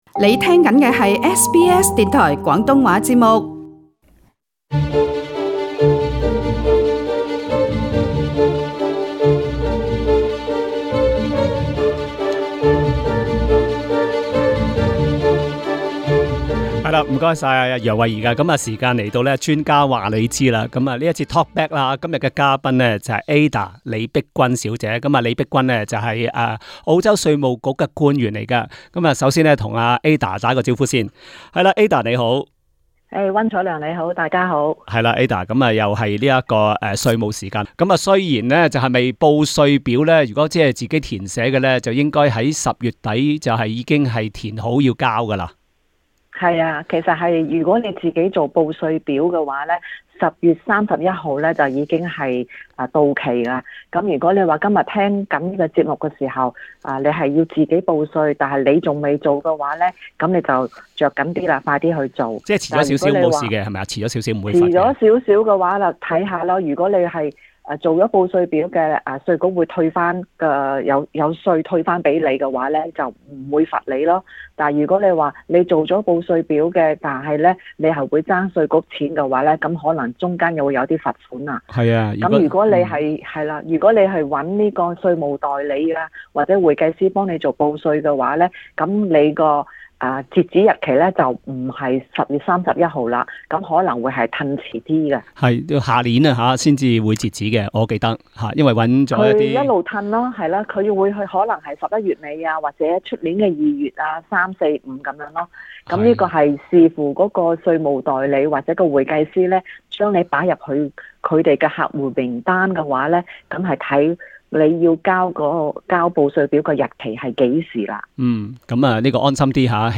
另外還會解答聽衆各方面的提問。